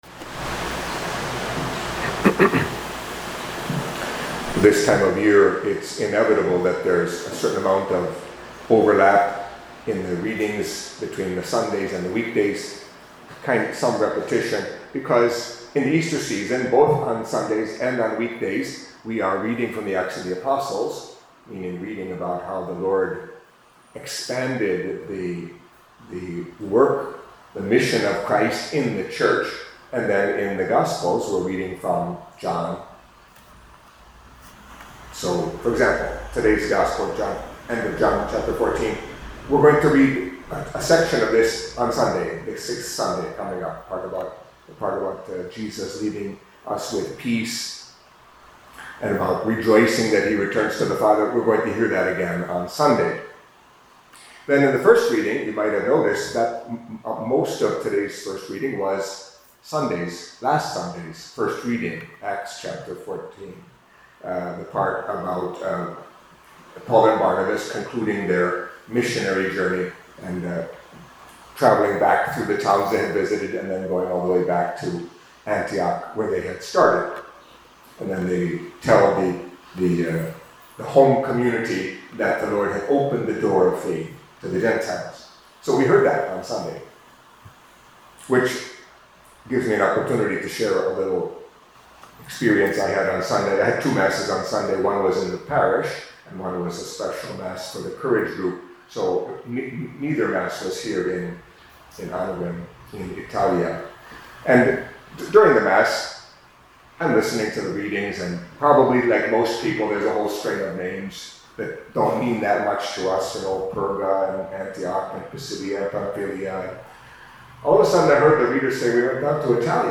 Catholic Mass homily for Tuesday of the Fifth Week of Easter